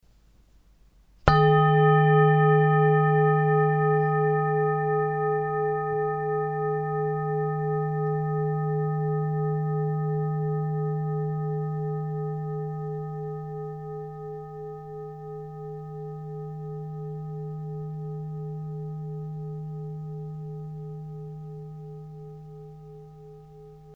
Tibetische Klangschale - ERDE WASSERSTOFFGAMMA + NEPTUN
Grundton: 156,06 Hz
1. Oberton: 425,34 Hz